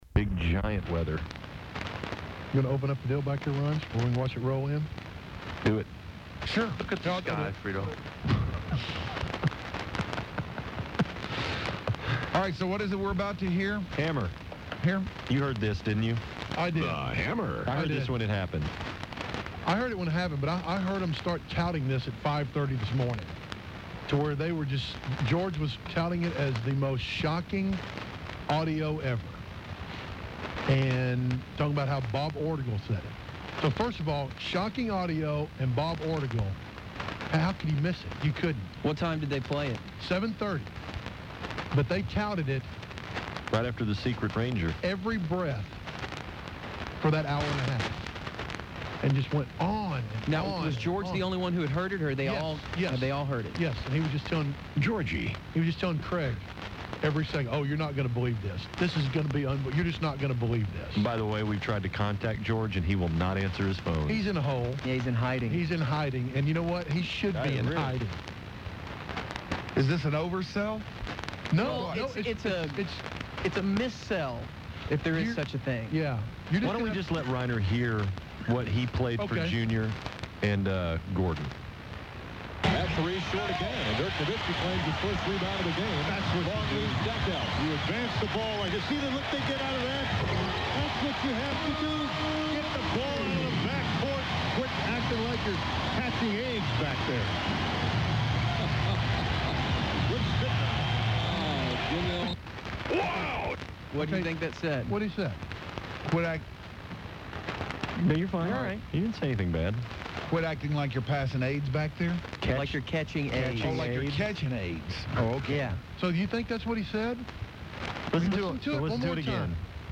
Mavs Public Service Announcement